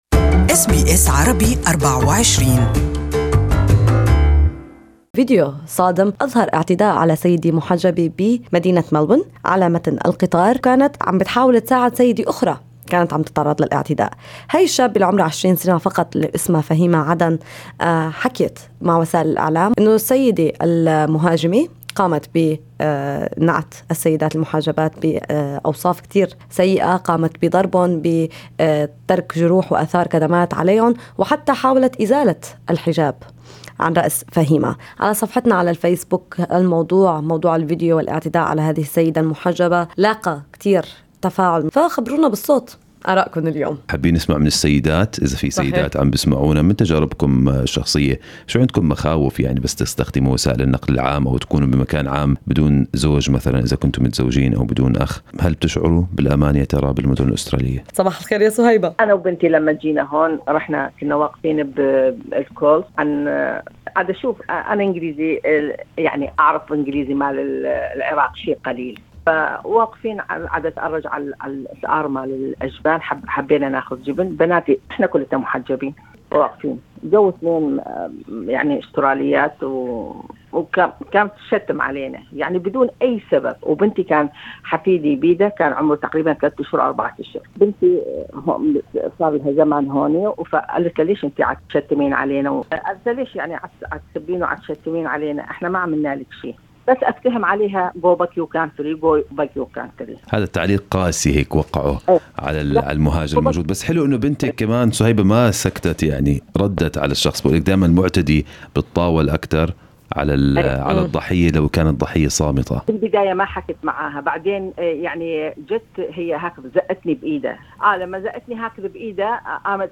Caller for SBS Arabic24 details the 'racist' attack she fell victim for along with her daughters upon her arrival in Australia.